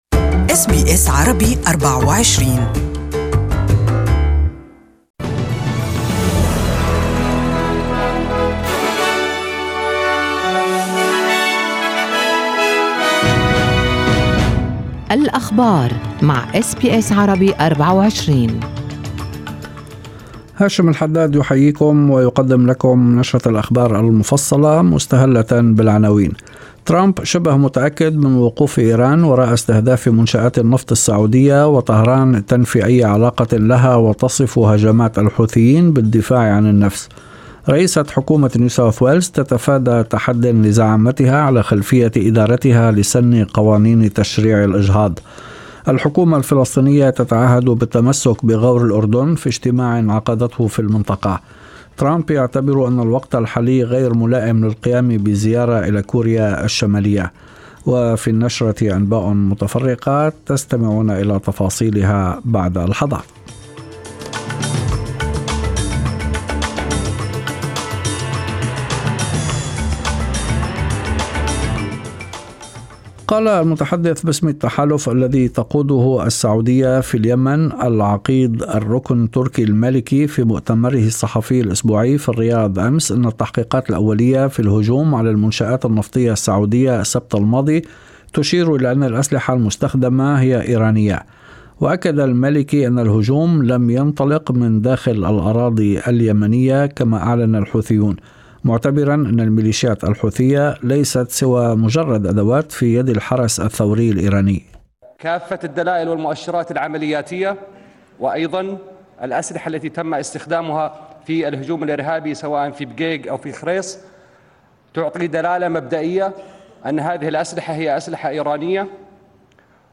This news bulletin is only available in Arabic.